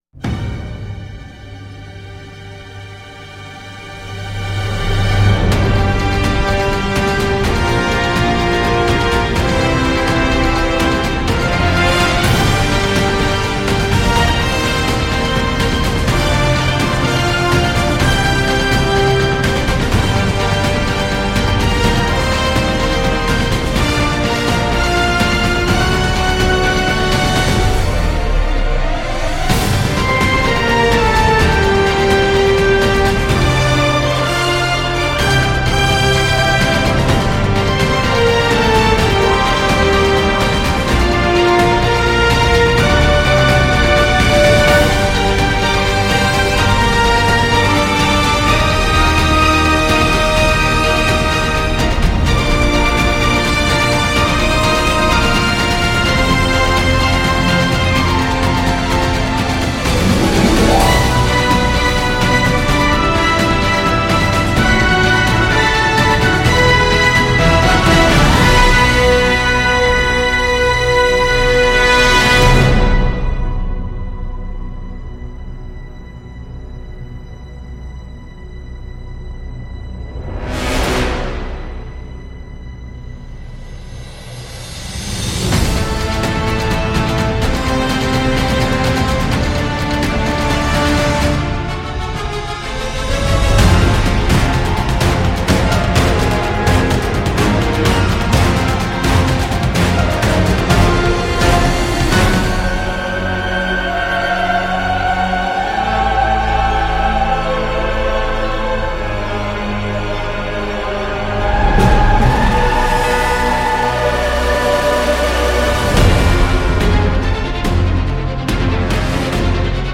L’efficacité des 90’s !
Foutraque.
Niveau romance, il y a de belles choses mélodiques.